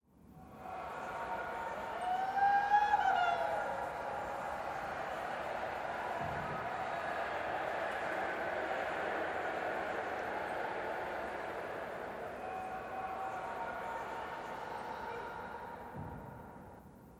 sfx_bg.opus